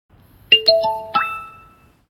点这里听一下），这个提示音是音调上升的，如果是下降音调，则是关机（
小奥开机提示音.MP3